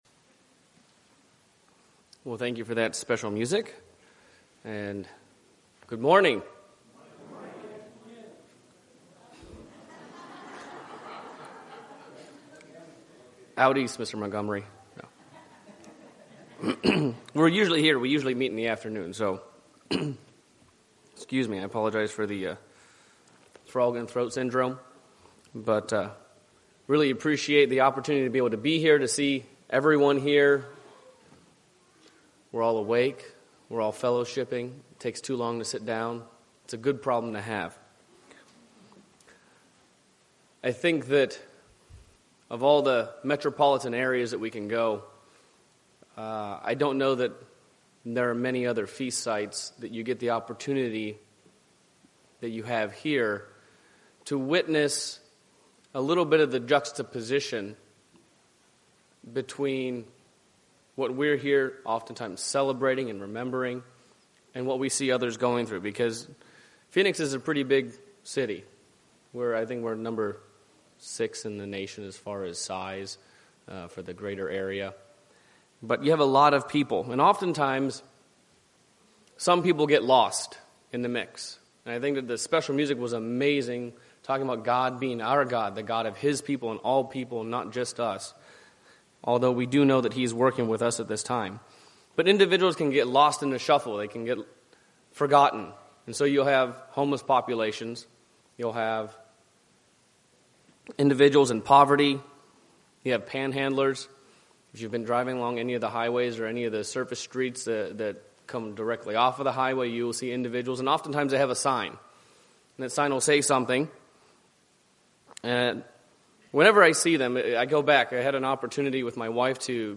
This sermon was given at the Phoenix, Arizona 2016 Feast site.